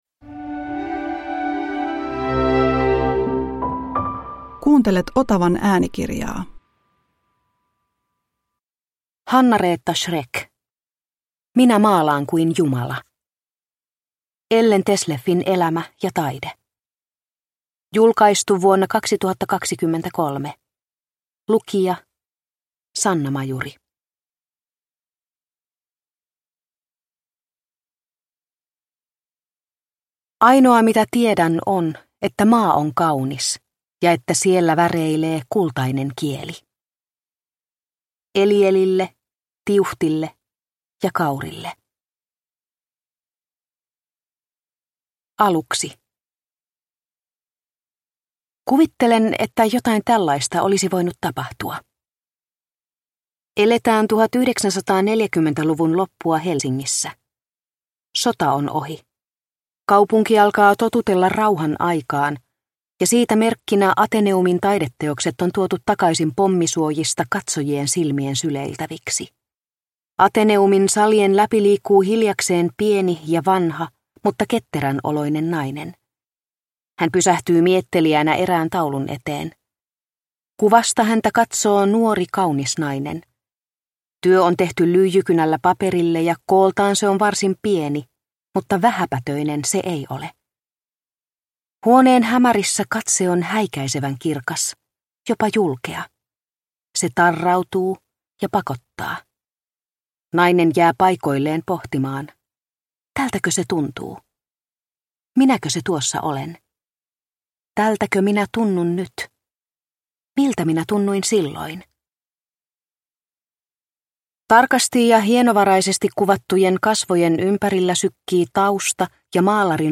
Minä maalaan kuin jumala – Ljudbok – Laddas ner